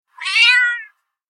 دانلود صدای ناله گربه کوچولو از ساعد نیوز با لینک مستقیم و کیفیت بالا
جلوه های صوتی